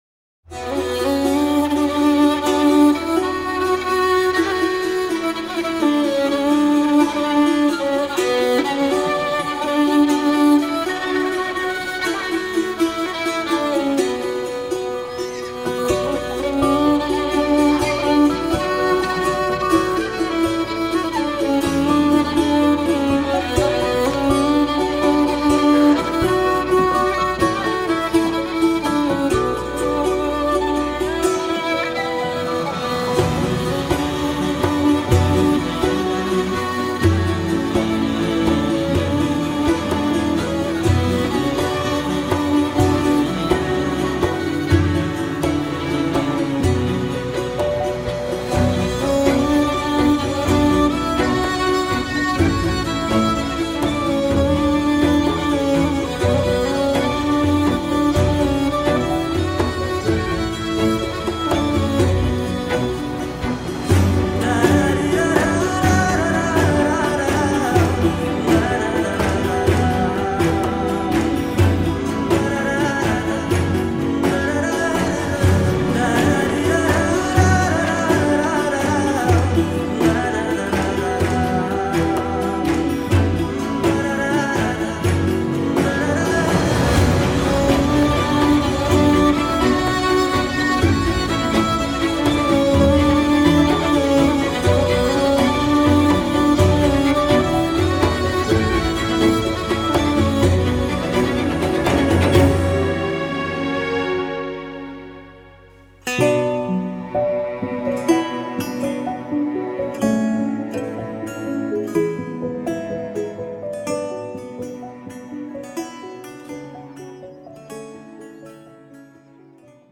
Vokal Versiyon
duygusal huzurlu rahatlatıcı fon müziği.